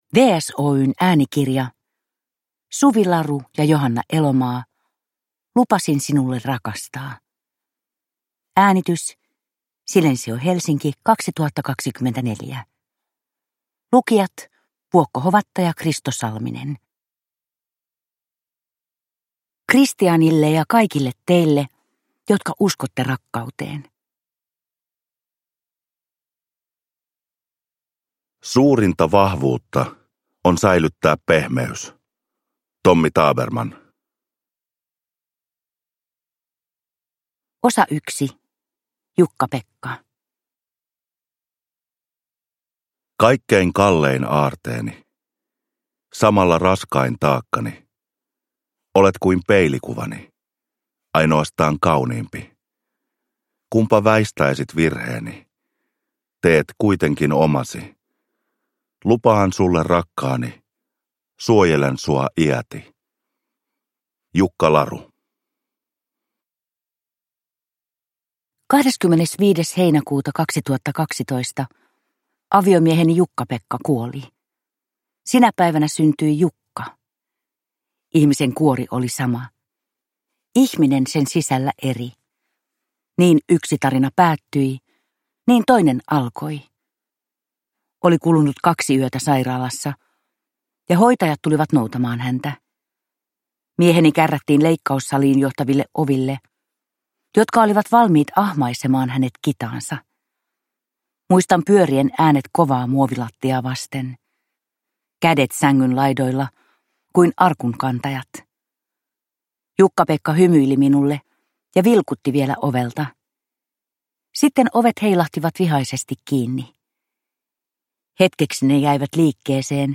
Lupasin sinulle rakastaa – Ljudbok